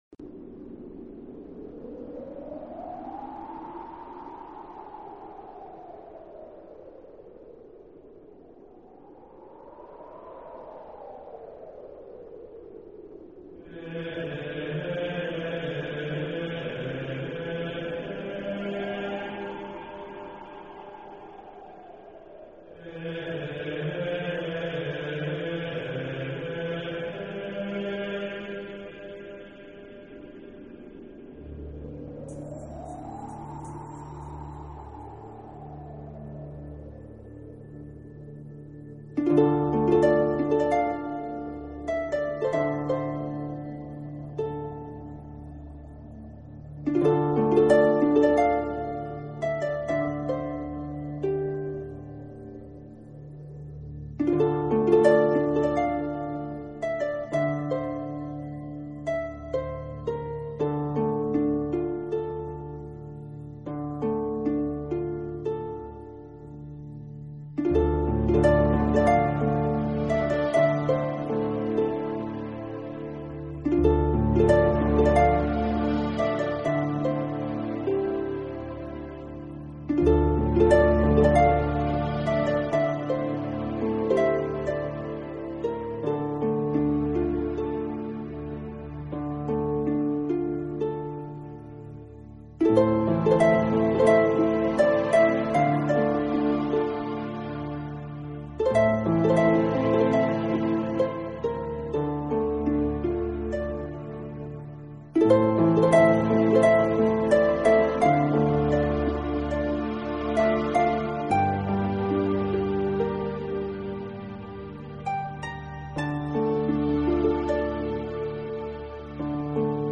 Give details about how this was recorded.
QUALiTY........: MP3 44,1kHz / Stereo